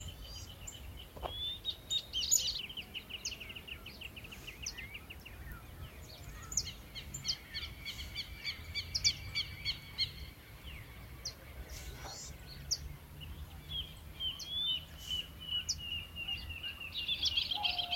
Noivinha (Xolmis irupero)
Nome em Inglês: White Monjita
Detalhada localização: Cañada Fragosa
Condição: Selvagem
Certeza: Observado, Gravado Vocal